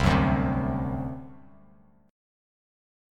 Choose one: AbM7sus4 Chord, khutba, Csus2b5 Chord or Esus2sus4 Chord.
Csus2b5 Chord